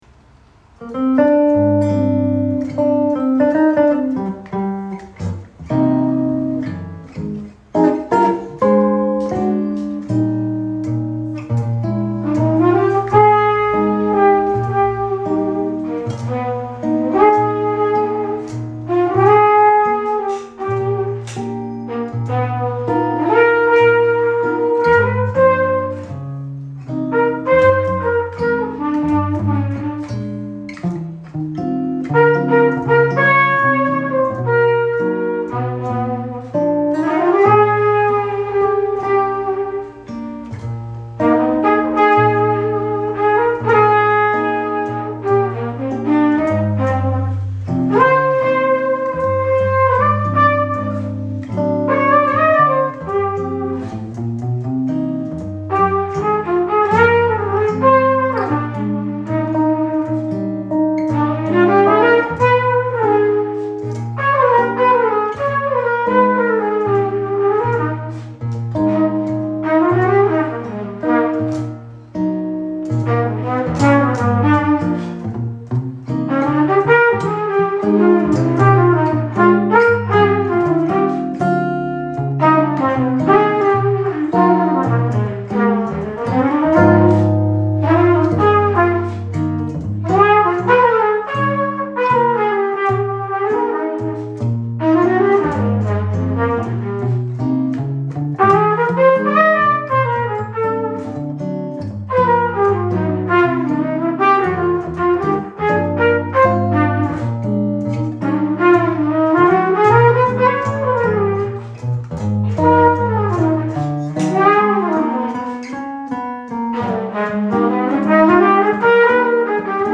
Mingeljazz
• Jazzband
• Duo/trio